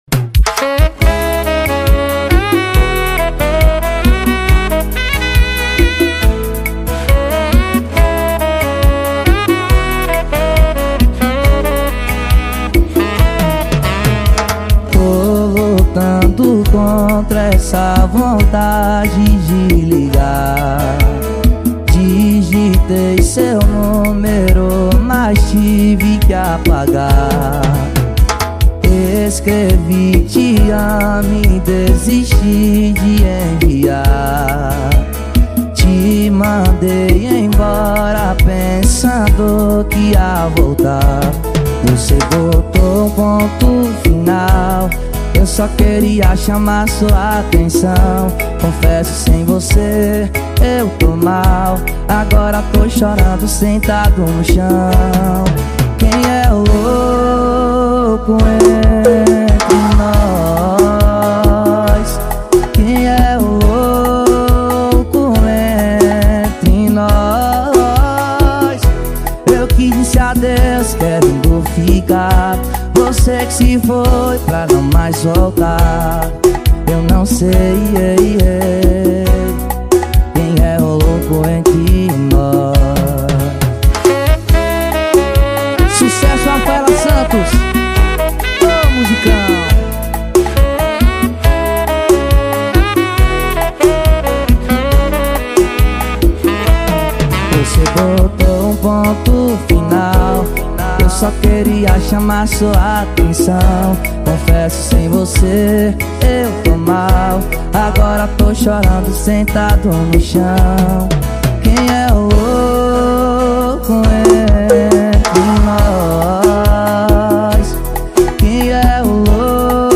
2024-07-14 00:14:10 Gênero: Sertanejo Views